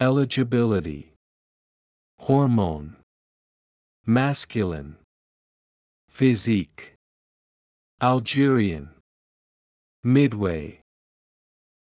eligibility /ɪˌlɪdʒəˈbɪləti/（名）資格、適格
hormone /ˈhɔːrmoʊn/（名）ホルモン
masculine /ˈmæskjəlɪn/（形）男性的な
physique /fɪˈziːk/（名）体格、肉体
Algerian /ælˈdʒɪriən/（形・名）アルジェリアの、アルジェリア人
midway /ˈmɪdˌweɪ/（副・名）途中で、中間点